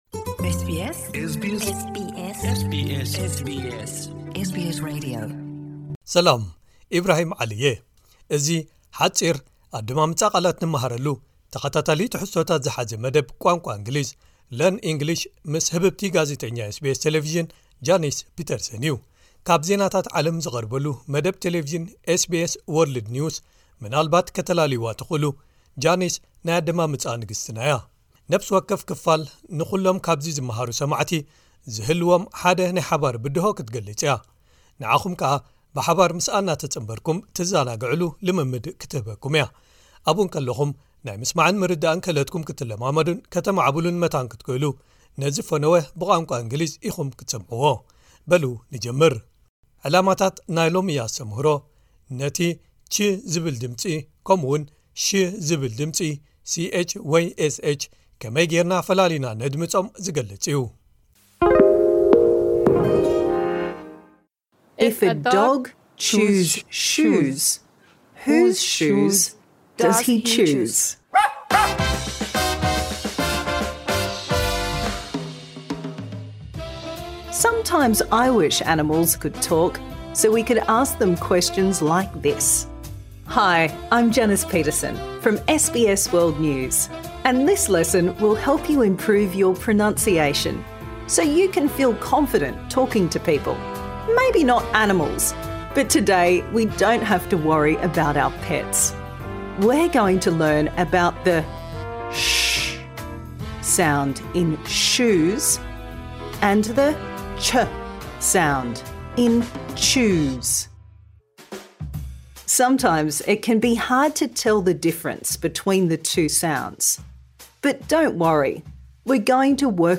Learning objectives: Can pronounce /tʃ / and /ʃ/ (ch and sh)